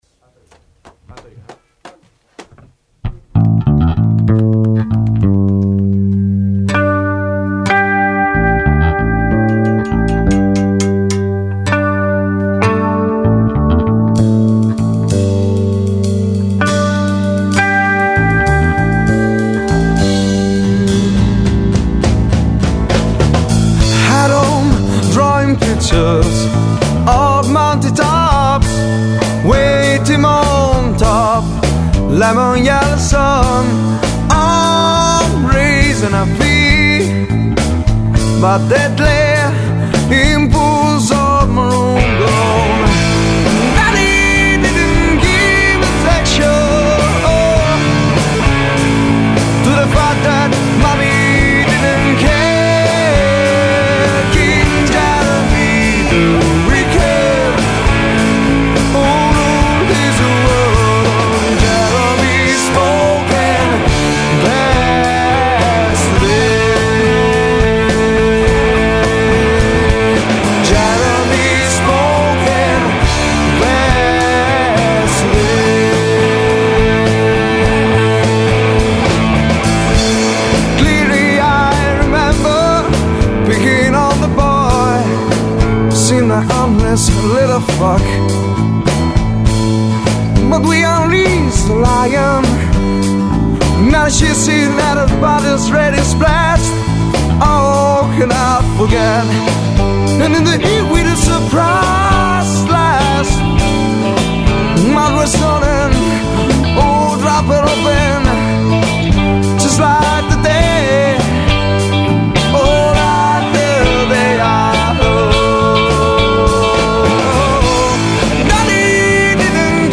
The GrungeRock Band
Chitarre, cori
Basso, cori
Batteria
Voce, chitarra acustica
all tracks recorded, mixed and mastered